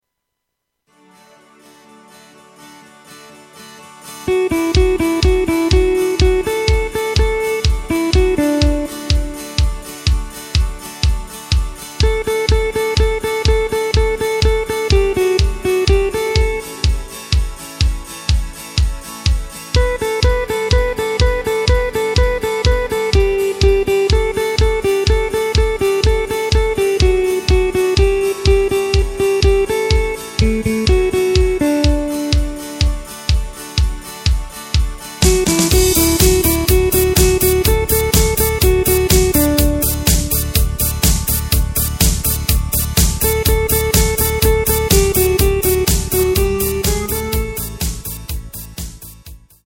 Takt:          4/4
Tempo:         124.00
Tonart:            D
Ostrock-Hymne aus dem Jahr 2019!